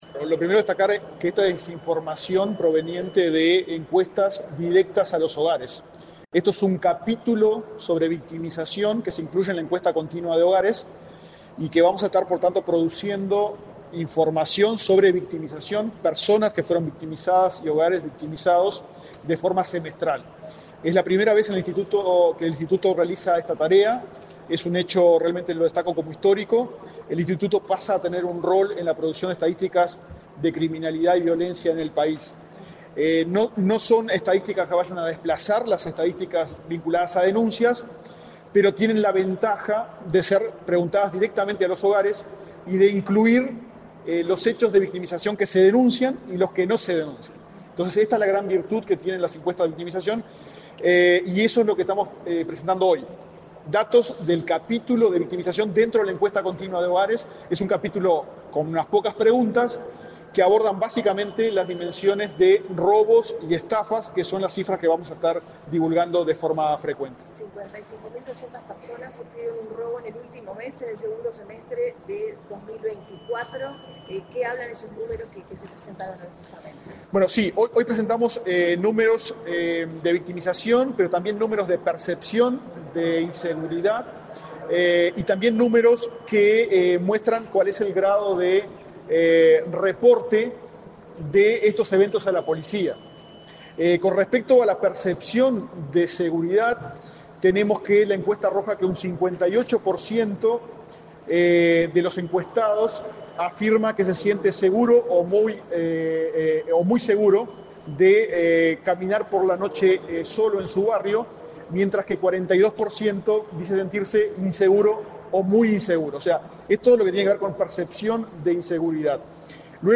Declaraciones a la prensa del director del INE, Diego Aboal
Declaraciones a la prensa del director del INE, Diego Aboal 13/02/2025 Compartir Facebook X Copiar enlace WhatsApp LinkedIn Tras participar en la presentación del Primer Informe Semestral de Estadísticas de Victimización, este 13 de febrero, el director del Instituto Nacional de Estadística (INE), Diego Aboal, realizó declaraciones a la prensa.